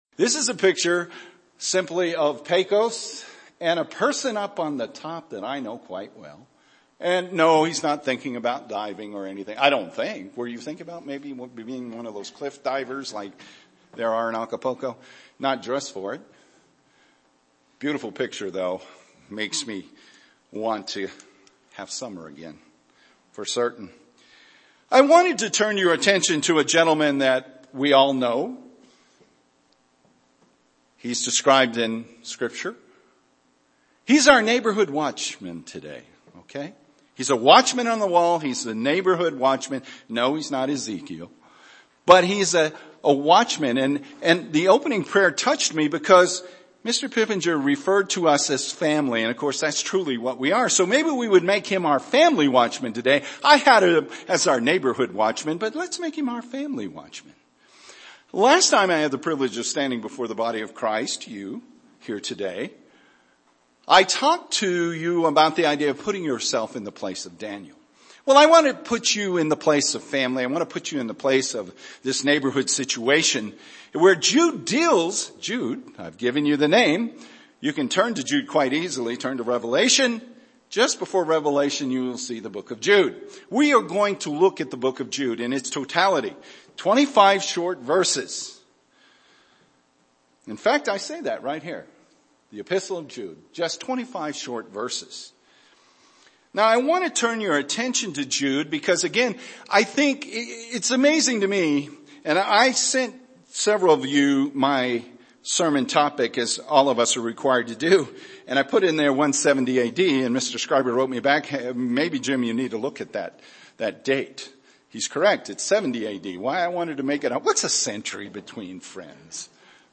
Given in Albuquerque, NM
UCG Sermon Studying the bible?